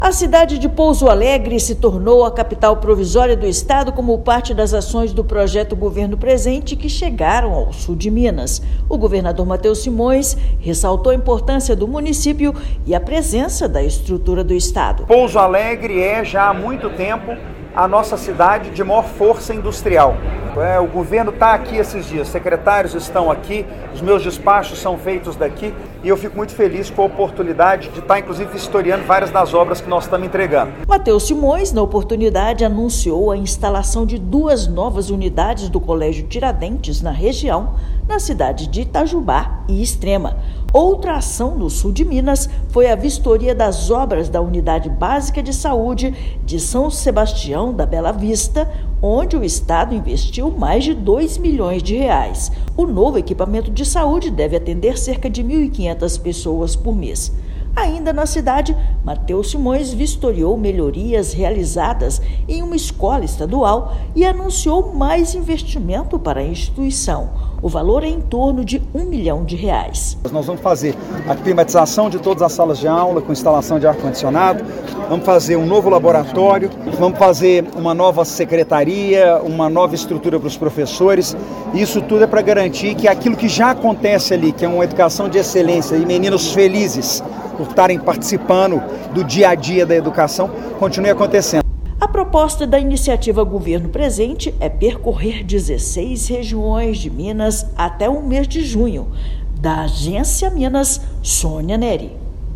Ações na região incluíram vistoria e entregas para a saúde e educação em São Sebastião da Bela Vista. Ouça matéria de rádio.